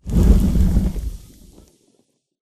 Pele_A01_Projectile.m4a